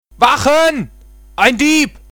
Deutsche Sprecher (m)
Ich w�rde sagen leicht genervte kaiserliche Wache oder Kaiserliches B�rgertum.